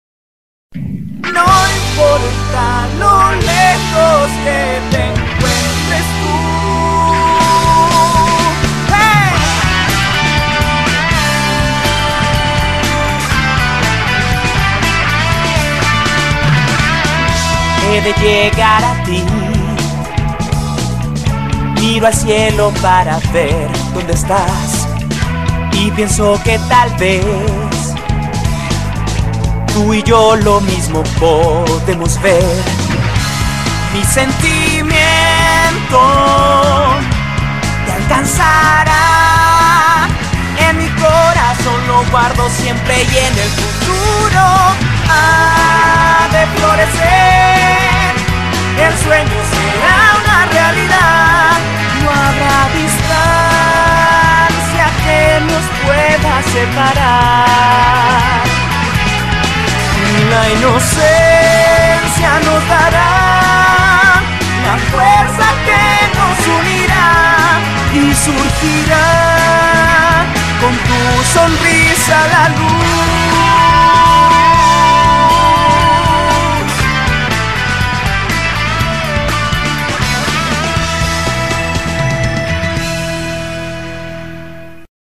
BPM121-121
Audio QualityPerfect (High Quality)
Ending 1 Latino